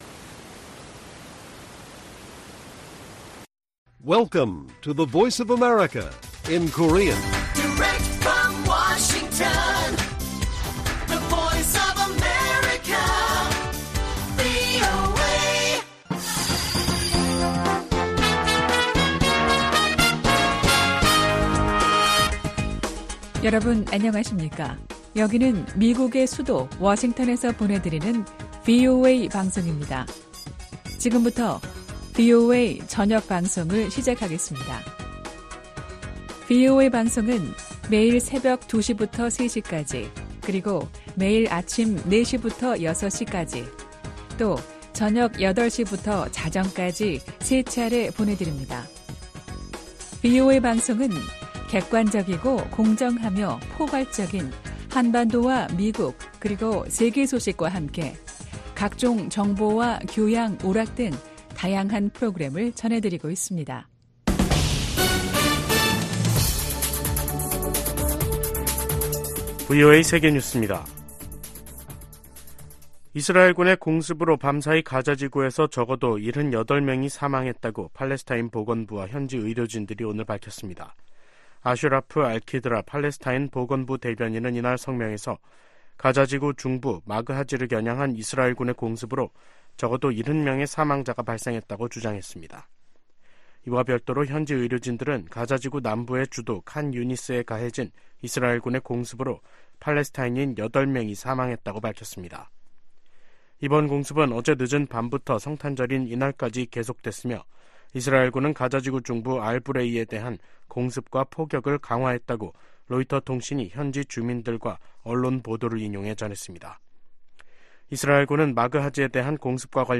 VOA 한국어 간판 뉴스 프로그램 '뉴스 투데이', 2023년 12월 25일 1부 방송입니다. 북한이 이번 주 노동당 전원회의를 개최할 것으로 보입니다. 전문가들은 이번 전원회의에서 핵 무력 강화를 지속하겠다는 강경 메시지를 내놓을 가능성이 크다고 보고 있습니다. 미국 국무부가 북한 영변 핵시설의 경수로 가동 정황에 심각한 우려를 표명했습니다.